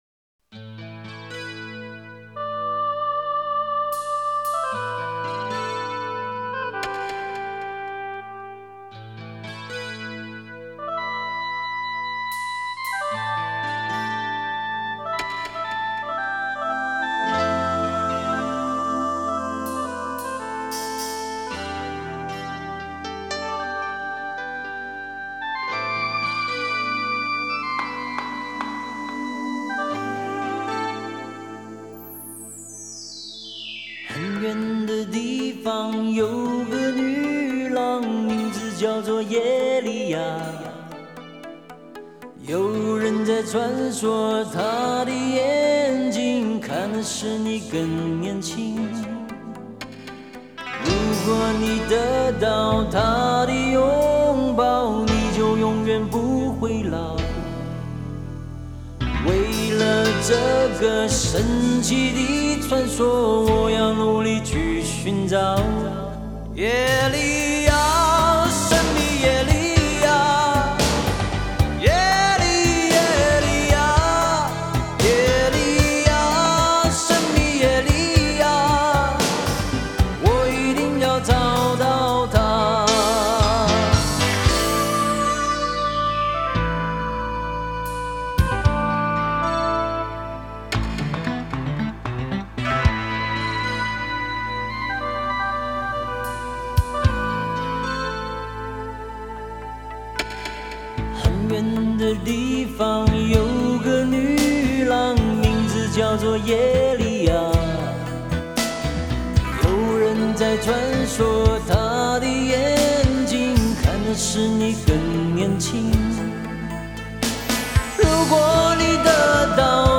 Ps：在线试听为压缩音质节选，体验无损音质请下载完整版 Publisher